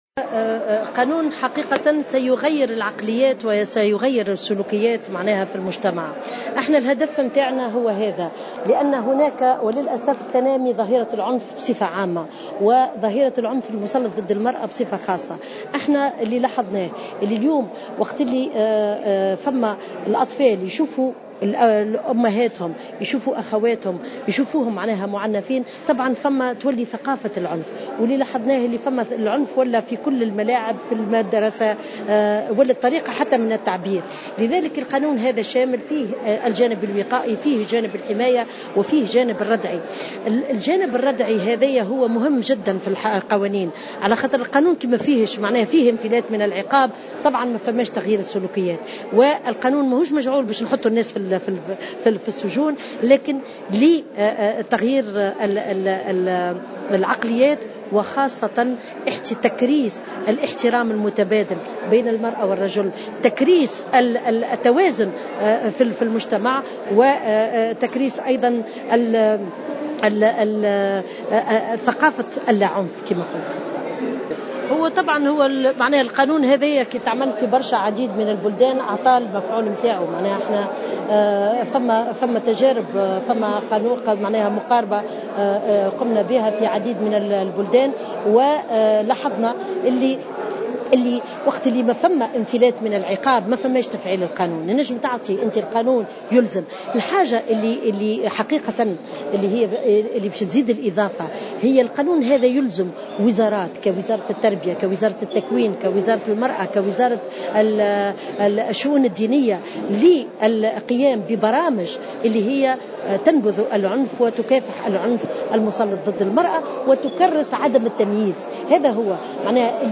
وأوضحت في تصريحات صحفية على هامش جلسة عامة بالبرلمان، أن تجارب الدول في هذا المجال أثبتت أن الجانب الردعي أفضل السبل لحماية المرأة حتى لا يوغل المعتدي في مضايقتها وايذائها، وضمان عدم افلاته من العقاب.